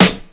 • 00s Rap Steel Snare Drum Sound F# Key 174.wav
Royality free snare drum tuned to the F# note. Loudest frequency: 2075Hz